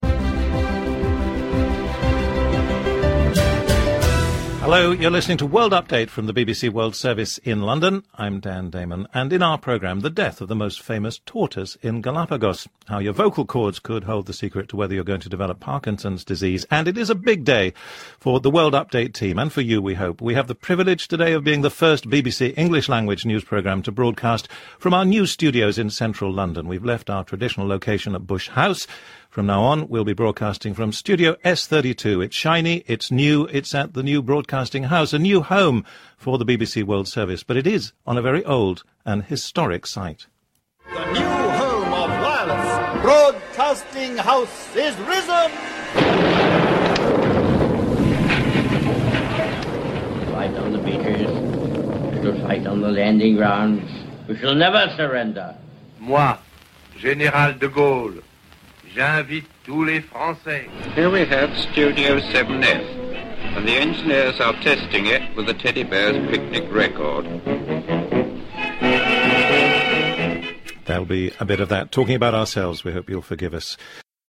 Here’s the opening of that 9 am edition of World Update.